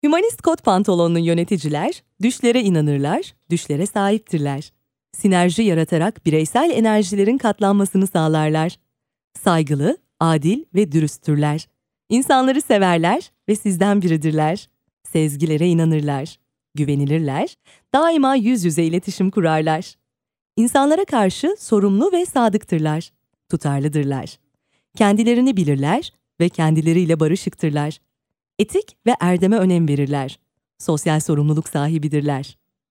Turkish female voice over talent, turkish e-learning female voice, turkish female narrator
Sprechprobe: eLearning (Muttersprache):